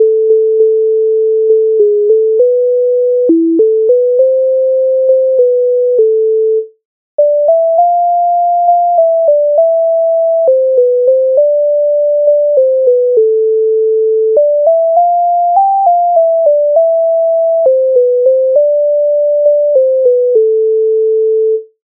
MIDI файл завантажено в тональності a-moll
Якби мені не тиночки Українська народна пісня
Ukrainska_narodna_pisnia_Yakby_meni_ne_tynochky.mp3